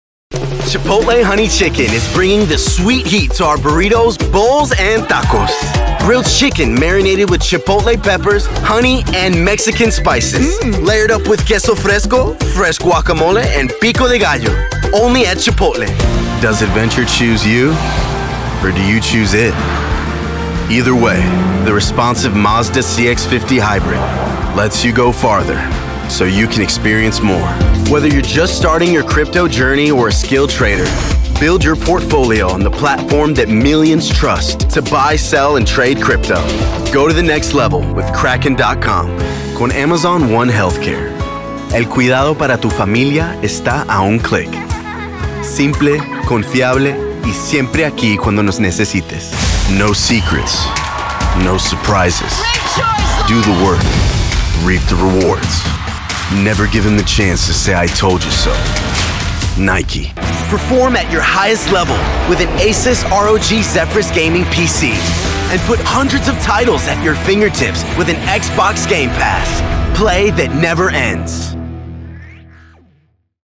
Broadcast-quality studio.
Commercial Demo
Commercial-Demo.mp3